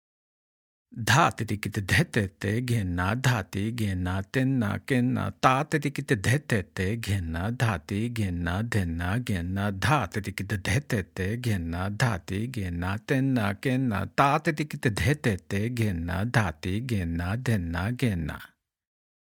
Spoken